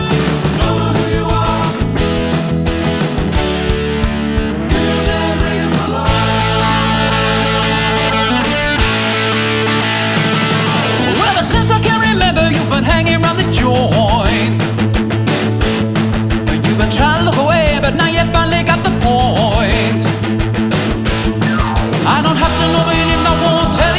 With Girl Two Semitones Down Rock 4:15 Buy £1.50